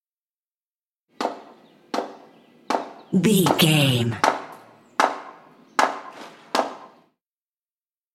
Construction ambience hit wood single
Sound Effects